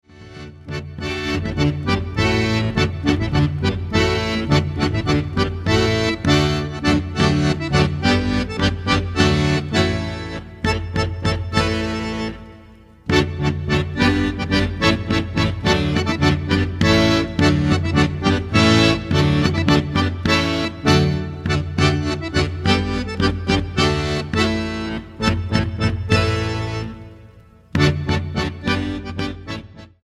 acordeón clásico
vihuela y guitarra renacentistas
percusiones
guitarra española y percusiones